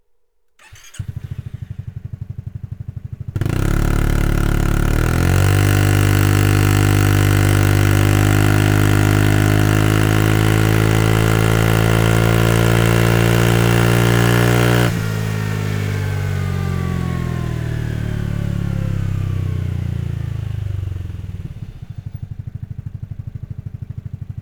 Akrapovic Slip-On Line (Edelstahl) Endschalldämpfer , mit EU-Zulassung; für
Mit dem Akrapovic Slip-On System bekommt Ihr Scooter diesen unverkennbaren tiefen und satten Akrapovic Sound – und auch die Optik wird enorm verändert.
Sound Akrapovic Slip-On